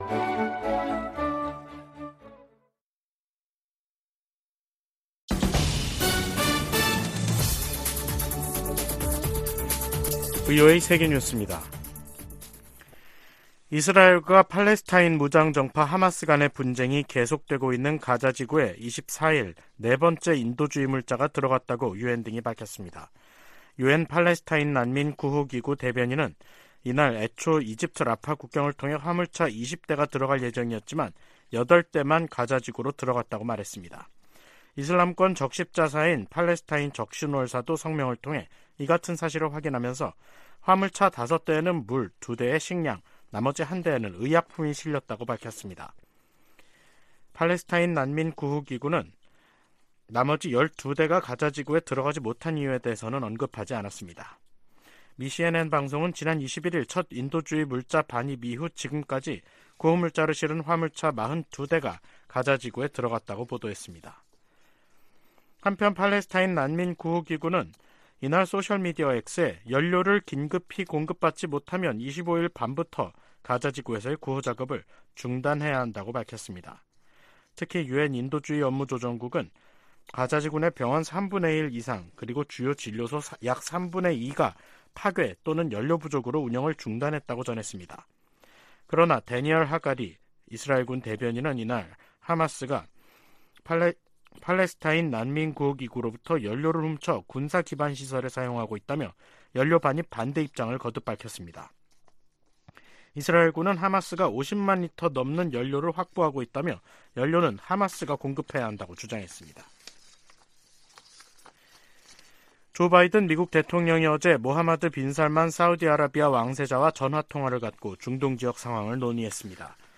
VOA 한국어 간판 뉴스 프로그램 '뉴스 투데이', 2023년 10월 25일 3부 방송입니다. 유럽연합(EU)이 탈북민 강제북송 문제가 포함된 북한인권 결의안을 유엔총회에 제출할 계획입니다. 한국은 유엔총회에서 북한과 러시아에 불법 무기거래를 즉각 중단라고 촉구했습니다.